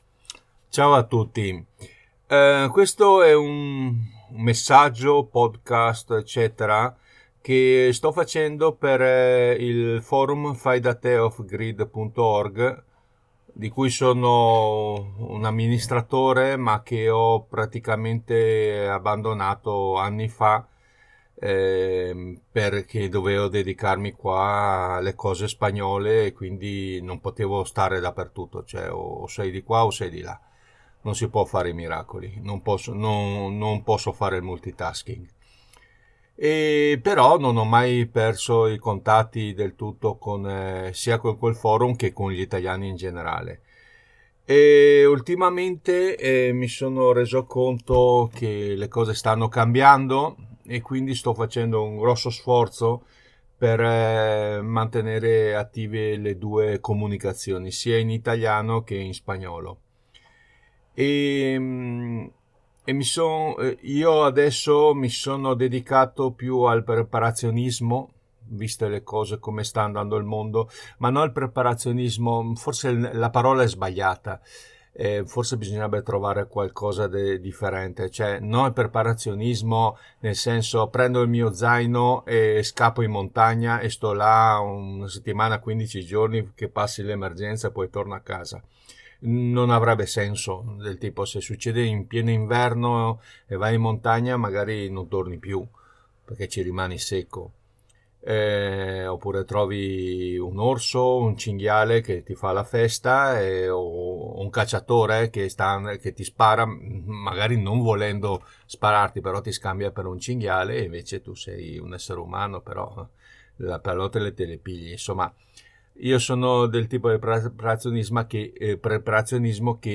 messaggio vocale/podcast